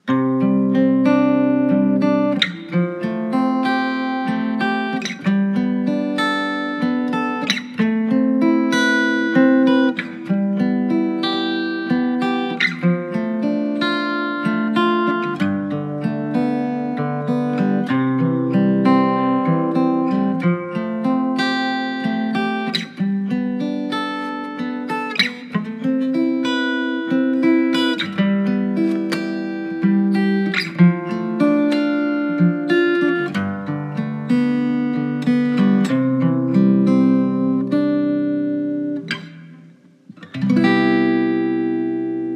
音乐疗法——第一期第一首 安慰，甚至是治愈，用简单而温暖的吉他音乐，让人们感受到亲切和温馨，找到共鸣和安慰 Comfort, even healing, using simple and warm guitar music to make people feel kind and warm, finding resonance and comfort 快来听听这首音乐，给你带来什么感受！